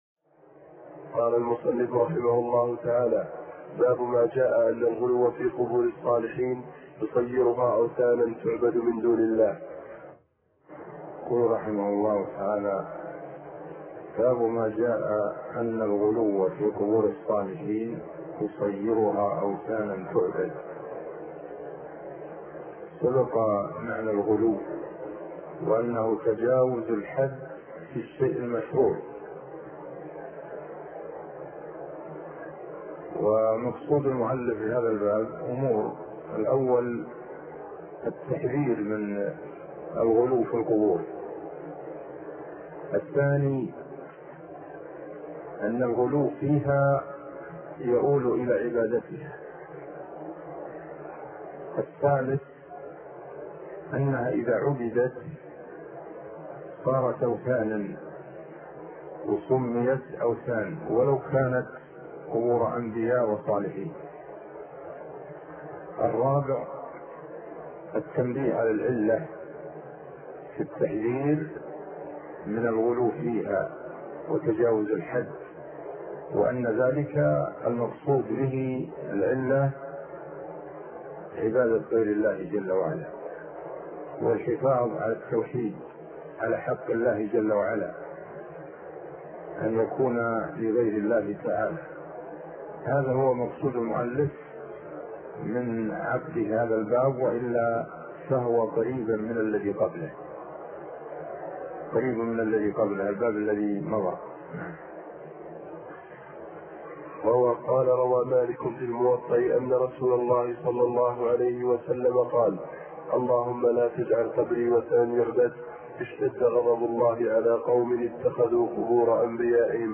عنوان المادة الدرس ( 63) شرح فتح المجيد شرح كتاب التوحيد تاريخ التحميل الجمعة 16 ديسمبر 2022 مـ حجم المادة 32.75 ميجا بايت عدد الزيارات 226 زيارة عدد مرات الحفظ 129 مرة إستماع المادة حفظ المادة اضف تعليقك أرسل لصديق